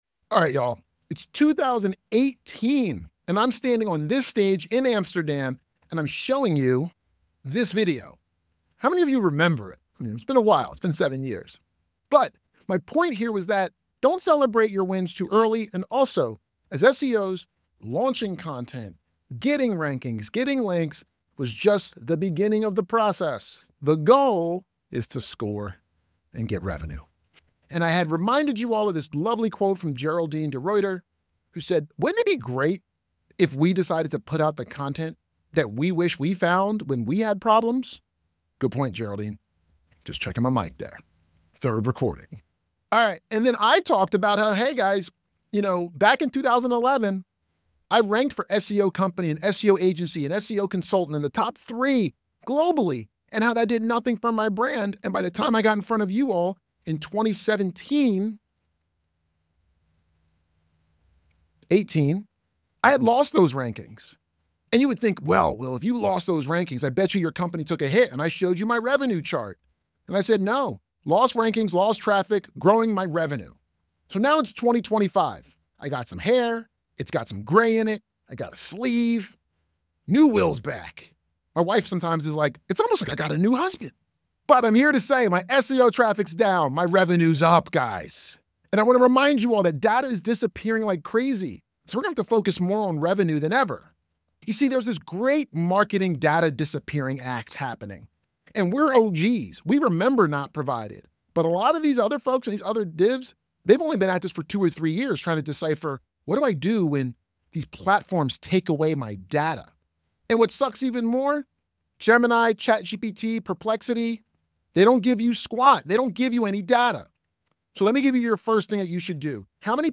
Our search traffic was down 41%, but leads went up 5%. In this keynote, he’ll review all the things he learned on his journey to justify the value of content in a world where customers are turning away from Google and towards AI